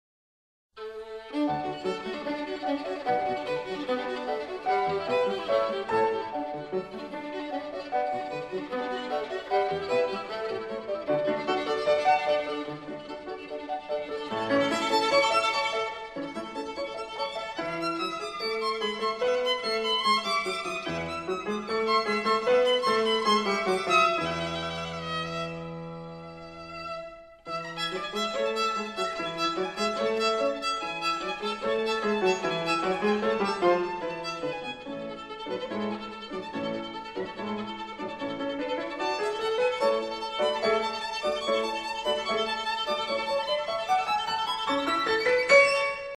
für Violine und Klavier / for violin and piano.